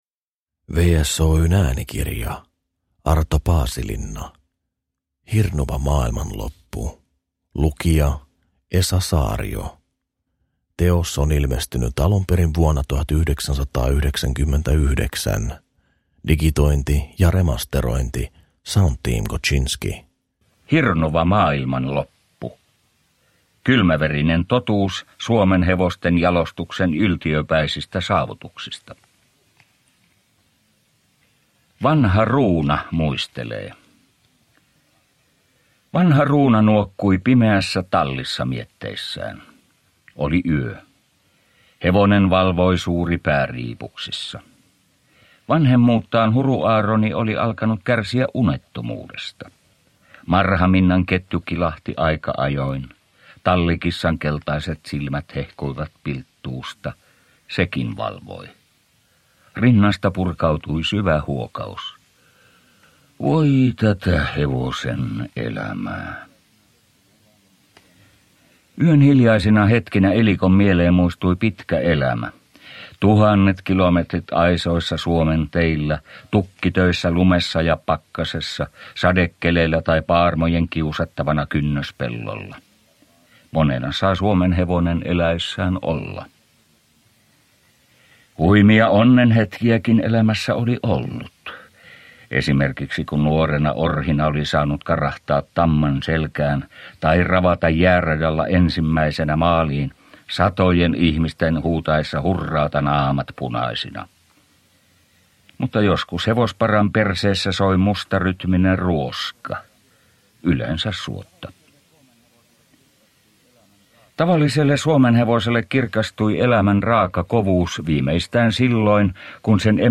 Hirnuva maailmanloppu – Ljudbok